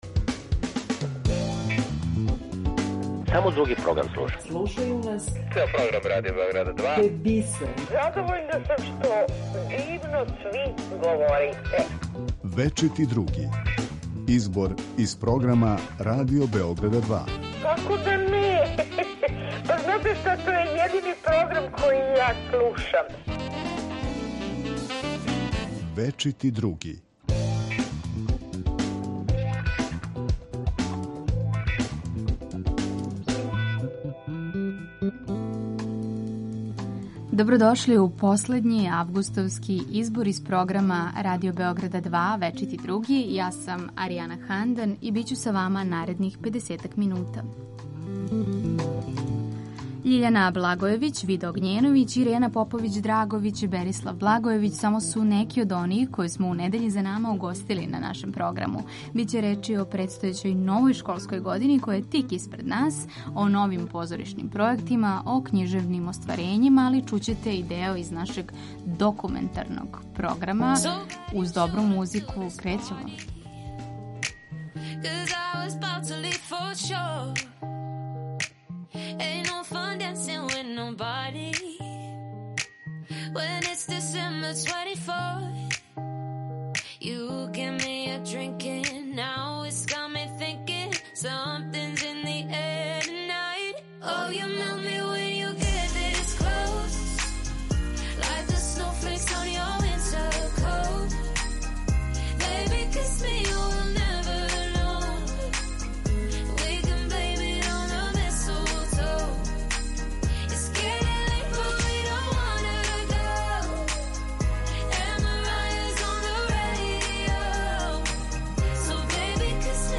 У недељном избору из програма Радио Београда 2 за вас издвајамо делове из емисија: Клуб 2, Маске, Спорови у култури, У првих пет, Говори да бих те видео...